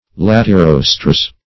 Search Result for " latirostres" : The Collaborative International Dictionary of English v.0.48: Latirostres \Lat`i*ros"tres\, n. pl.